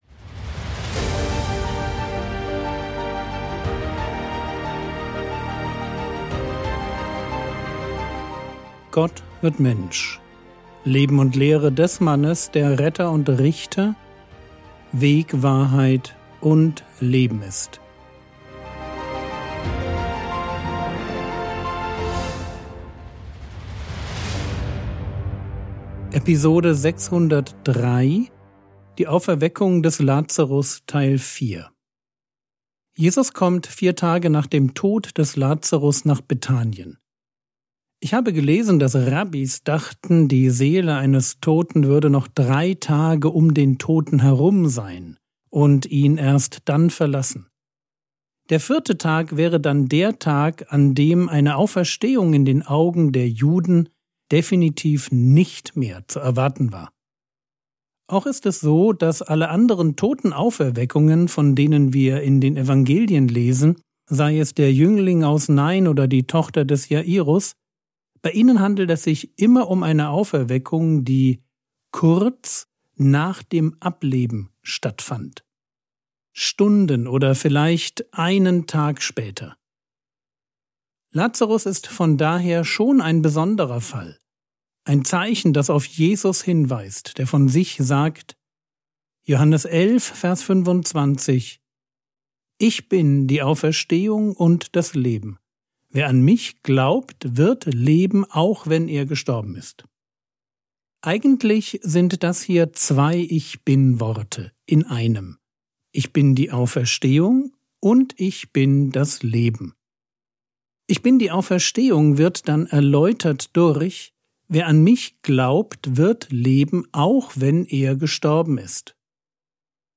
Episode 603 | Jesu Leben und Lehre ~ Frogwords Mini-Predigt Podcast